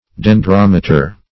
Search Result for " dendrometer" : The Collaborative International Dictionary of English v.0.48: Dendrometer \Den*drom"e*ter\, n. [Gr. de`ndron tree + -meter: cf. F. dendrom[`e]tre.] An instrument to measure the height and diameter of trees.